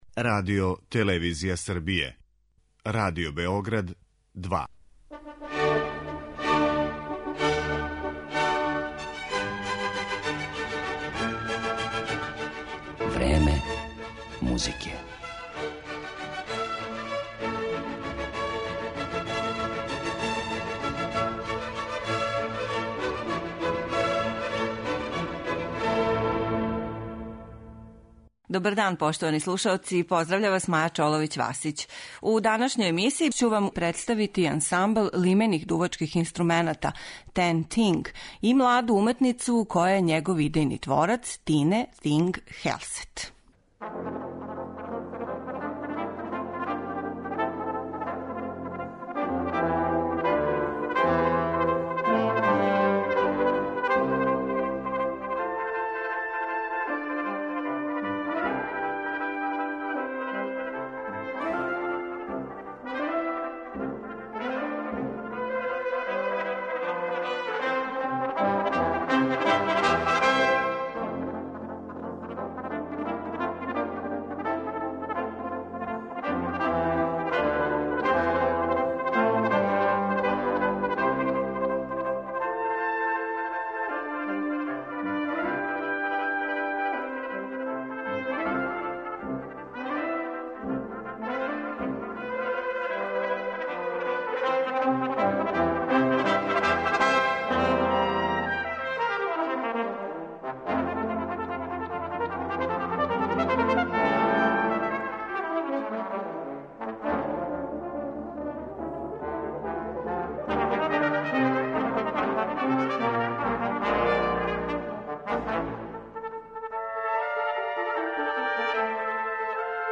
Данашња емисија је посвећена младој норвешкој уметници, виртуозуу на труби - Тине Тинг Хелсет и аснмблу лимених дувачких инструмената TenThing , који је 2007. године основала.
Десеточлани, искључиво женски ансамбл негује широк репертоар - од дела Моцарта, Грига, Бизеа, до композиција савремених аутора, који редовно представља на угледним фестивалима и у реномираним концертним дворанама.